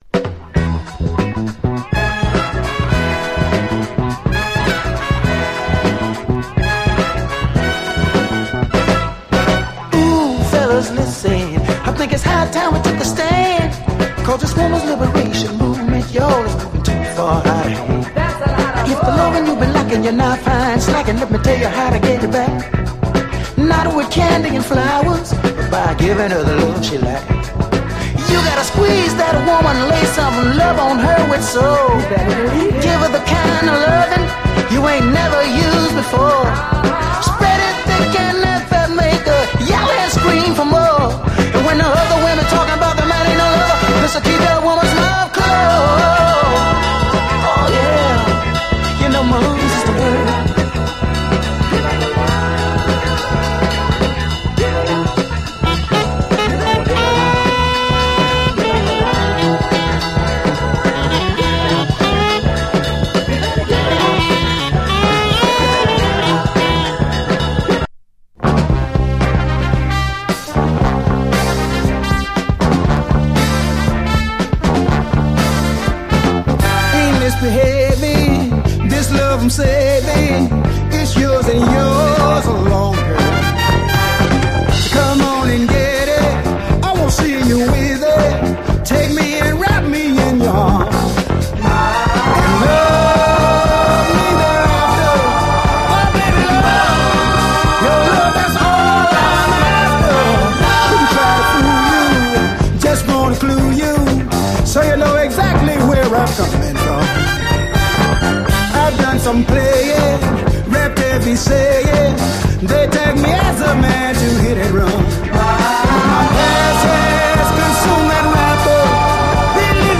SOUL, JAZZ FUNK / SOUL JAZZ, 70's～ SOUL, JAZZ
見過ごされているヤング・ソウル
男臭さ溢れるレアグルーヴ・チューン